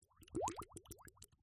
spongebob-transition-end.mp3